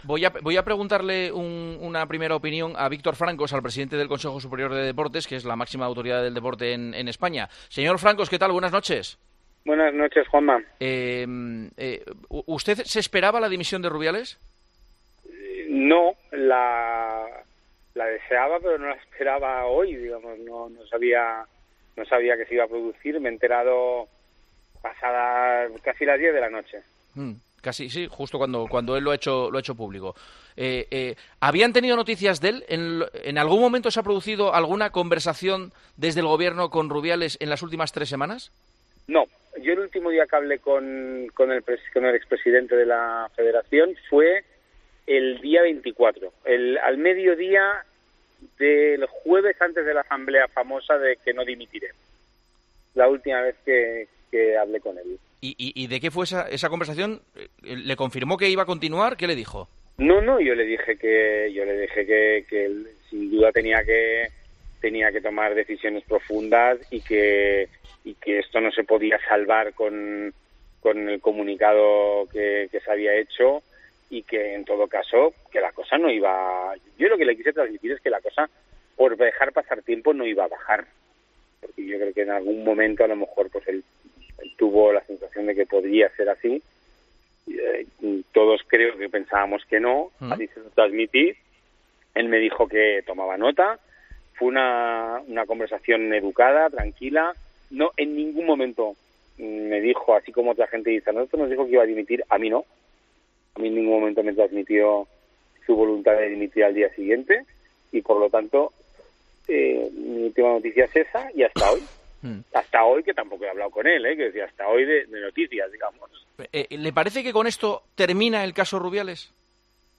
Con Paco González, Manolo Lama y Juanma Castaño